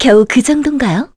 Selene-Vox_Skill4_kr.wav